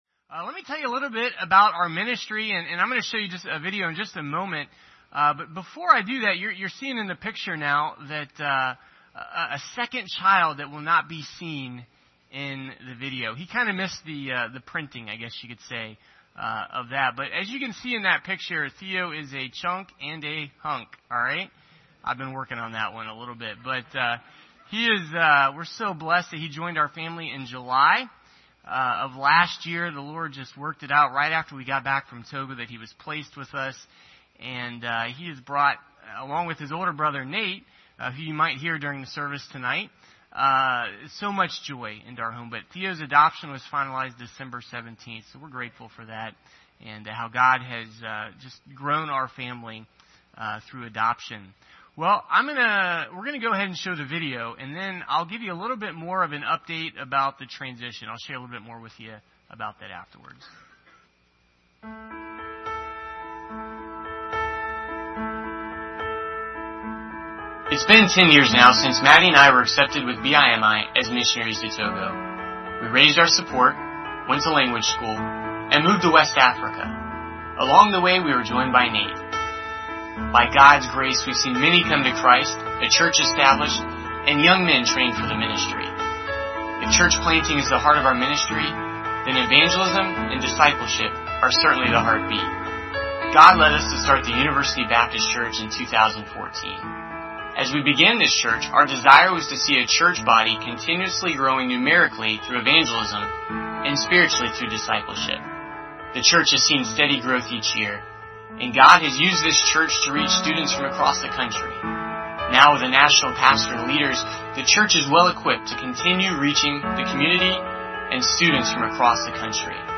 Missionary Reports